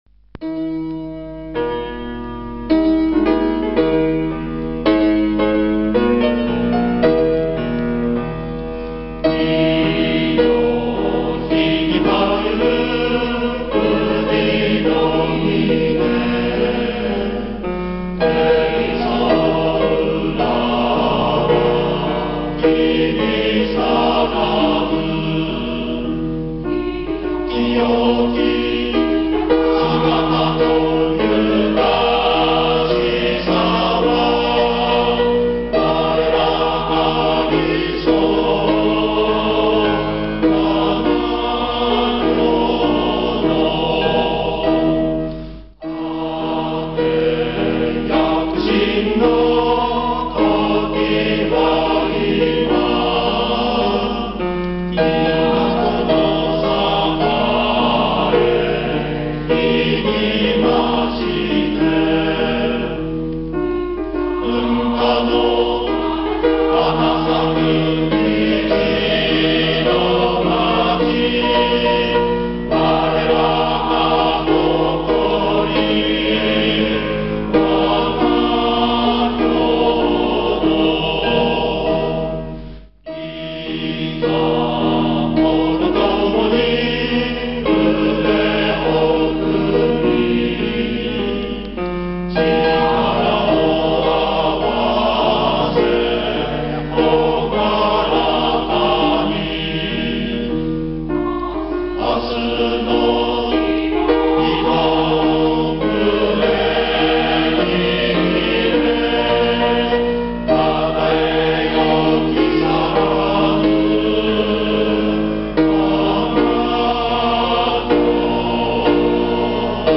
木更津市民歌（男声パート） （mp3） (音声ファイル: 4.8MB)